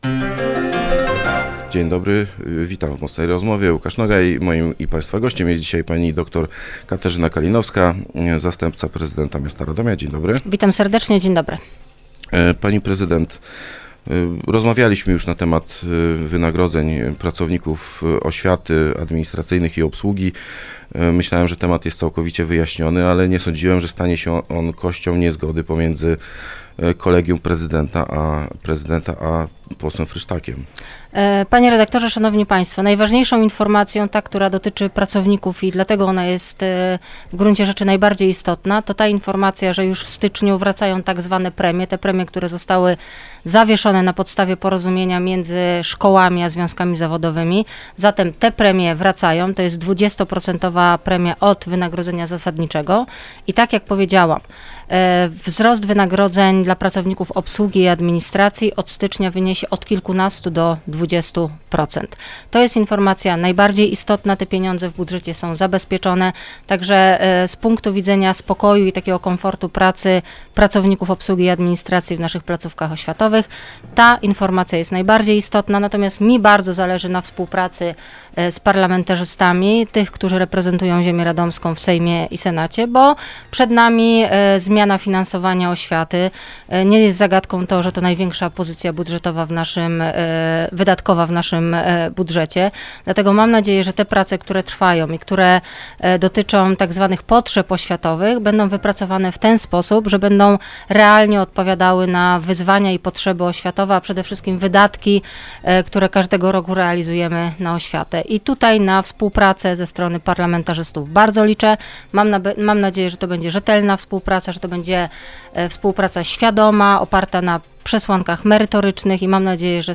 Wiceprezydent Radomia Katarzyna Kalinowska była gościem